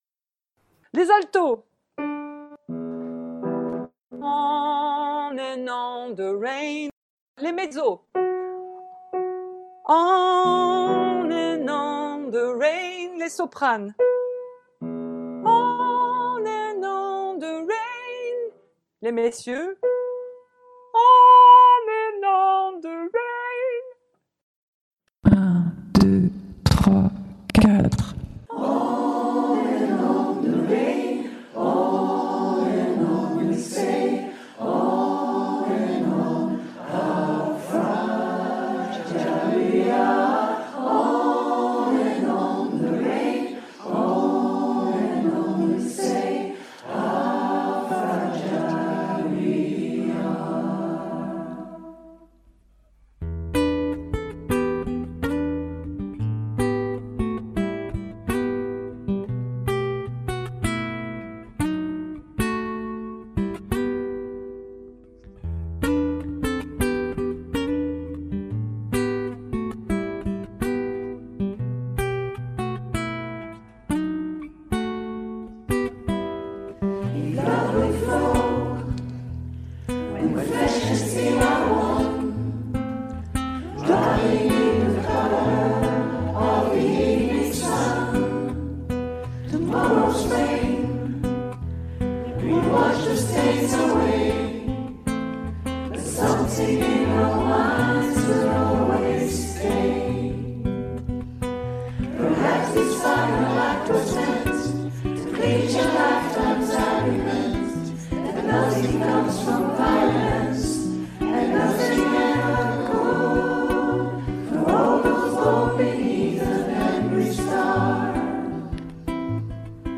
Fragile Tutti sur Guitare 1